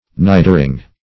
Nidering \Ni"der*ing\